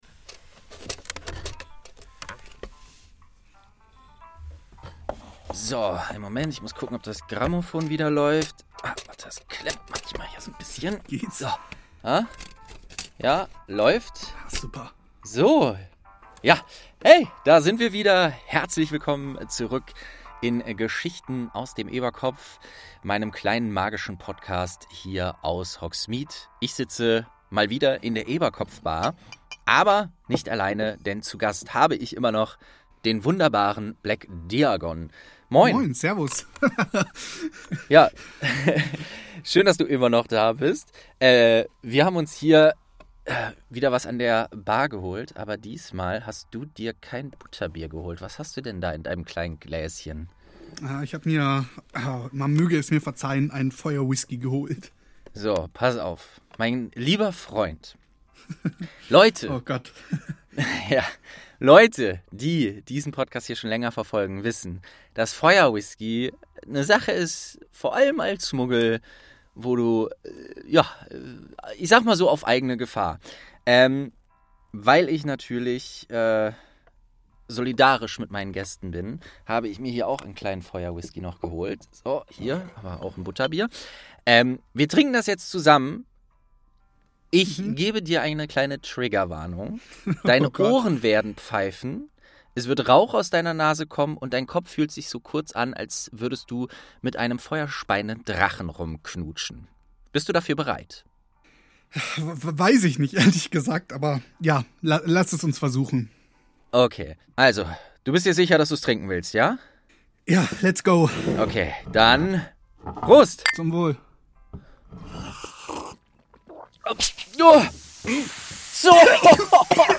St. 2 ~ Geschichten aus dem Eberkopf - Ein Harry Potter Hörspiel-Podcast Podcast